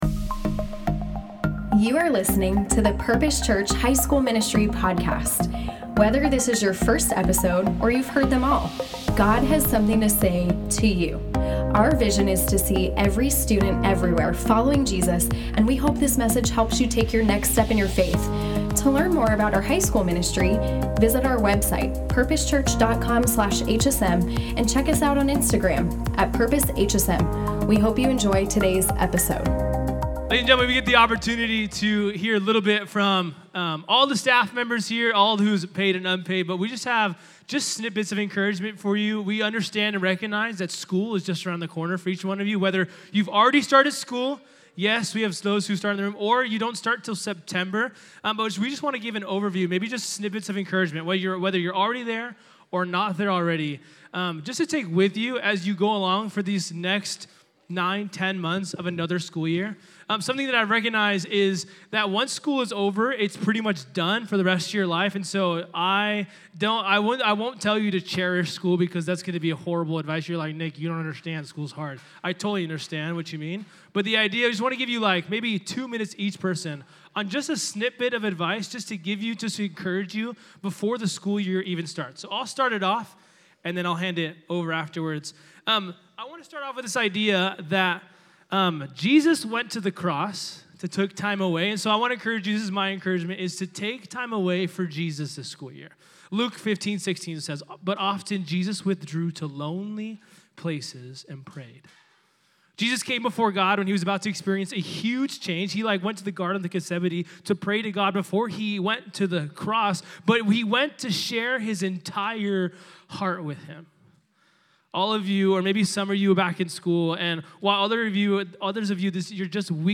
This week, at our special HSM and JHM joint service for Backyard Night, we enjoyed a time of encouragement from your very own Student Ministries Staff!